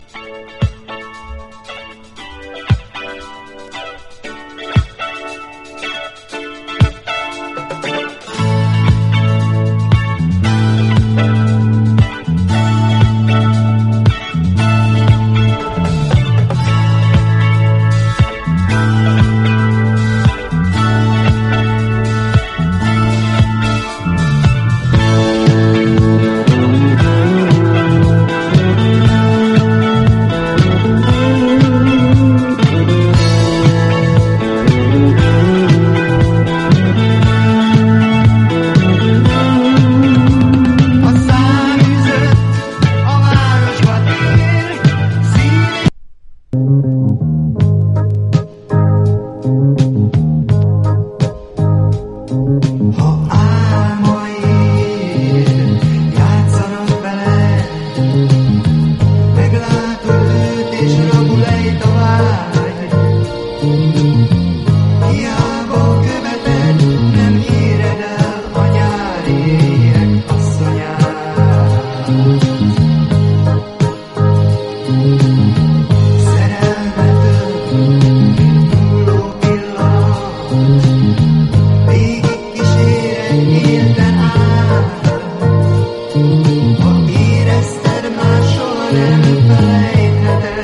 EASY LISTENING / VOCAL / JAPANESE
情緒たっぷりのカタコト日本語歌唱でカヴァー！